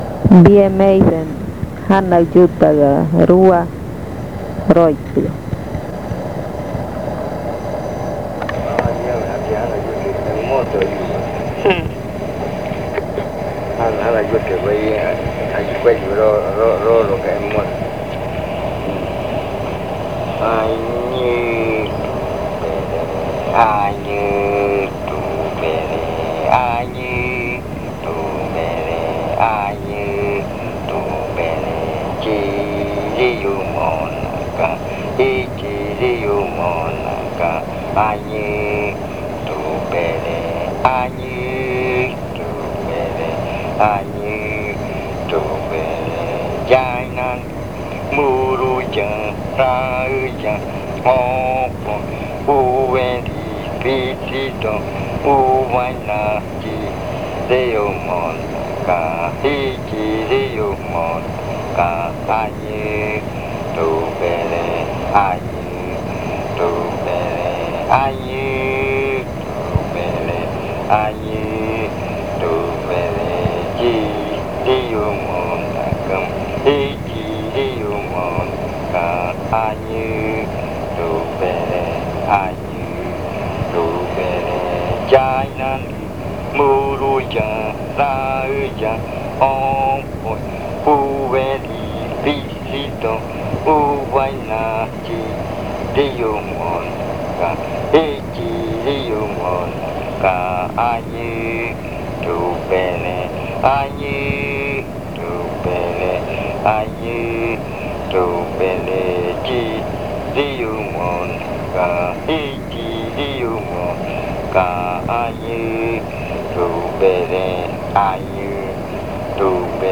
Leticia, Amazonas
Canto para cantar al otro día del baile. (Casete original